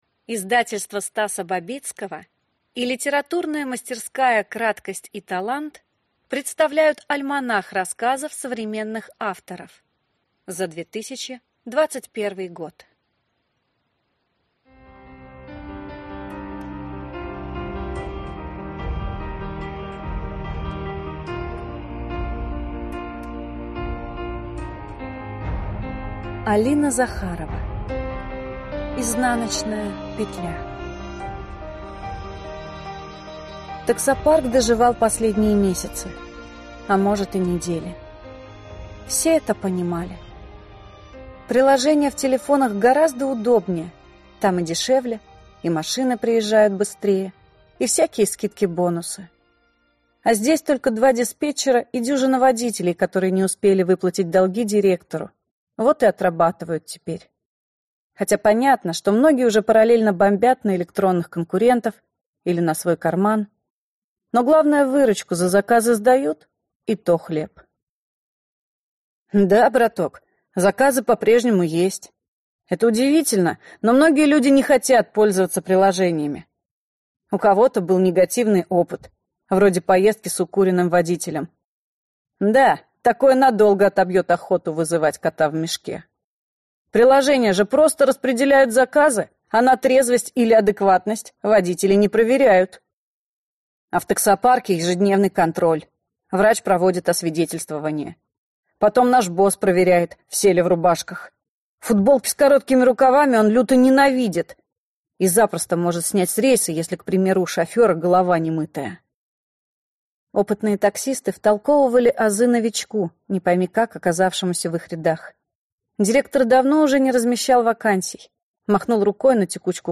Аудиокнига «Краткость и талант». Альманах-2021 | Библиотека аудиокниг